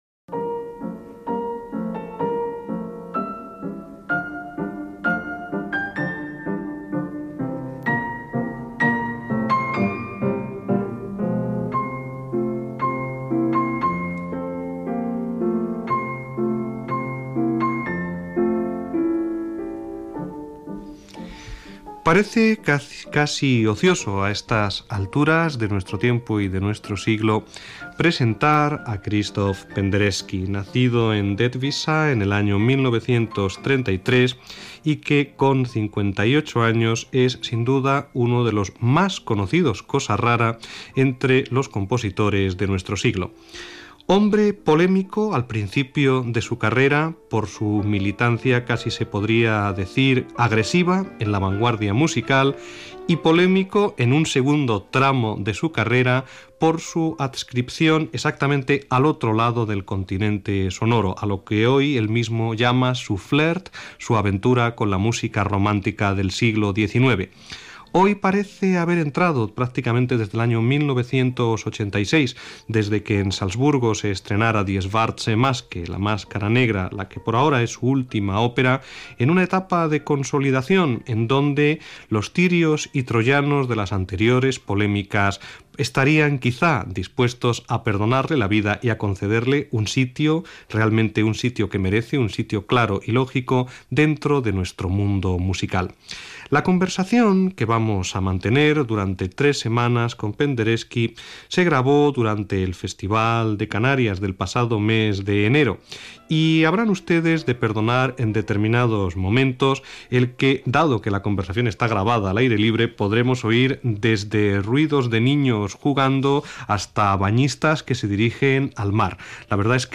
Entrevista al compositor Krzysztof Eugeniusz Penderecki feta a Canàries